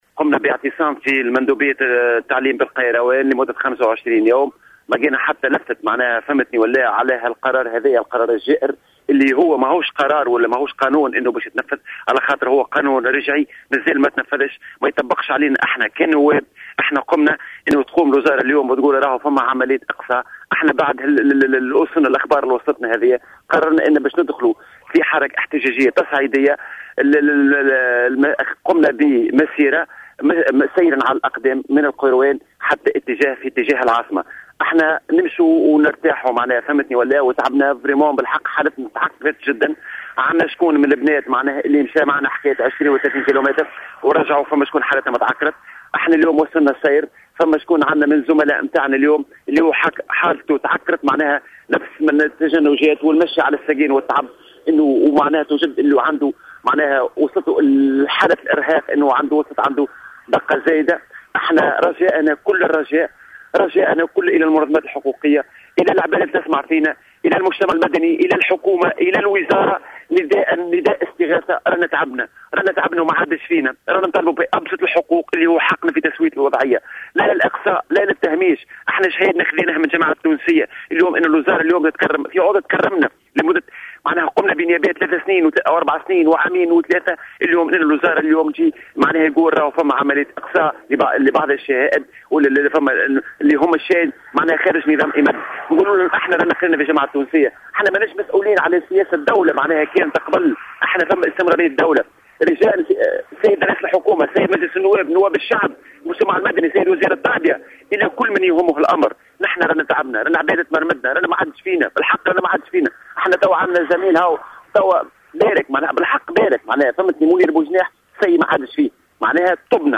في تصريح هاتفي للجوهرة أف أم